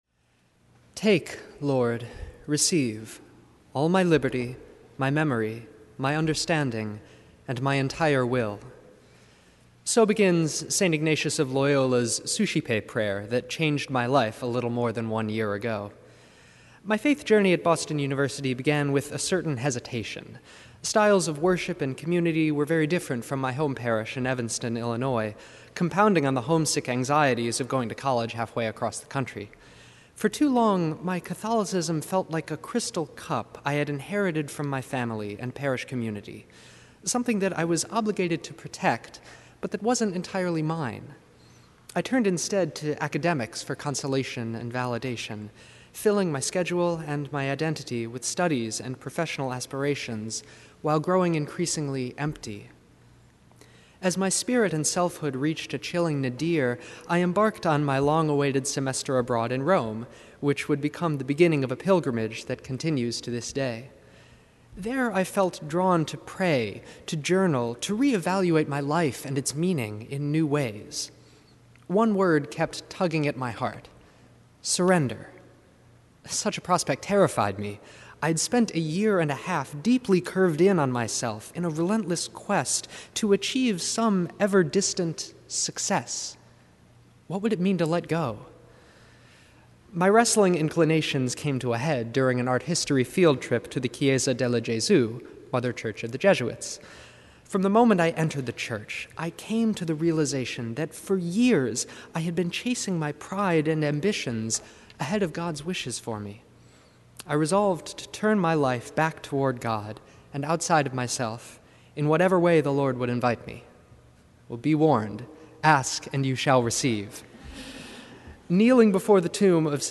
Graduating students share personal stories during Marsh Chapel’s “This I Believe” Sunday